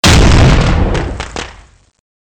firecracker_explosion.wav